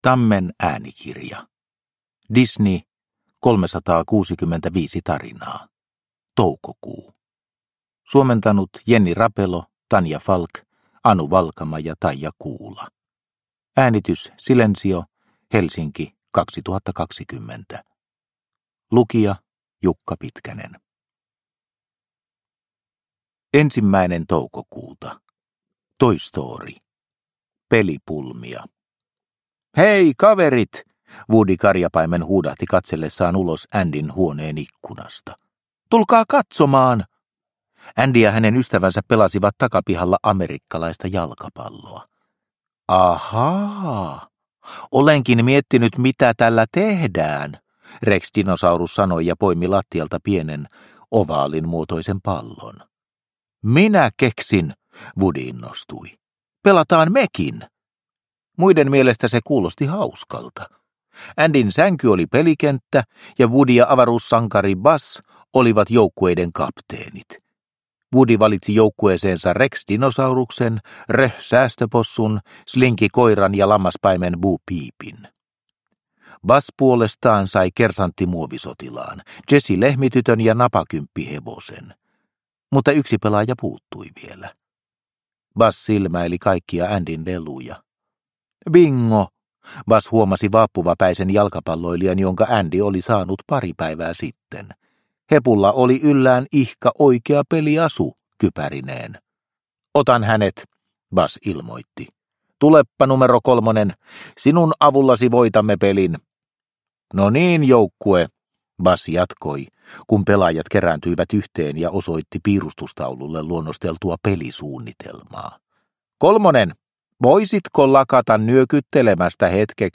Disney 365 tarinaa, Toukokuu – Ljudbok – Laddas ner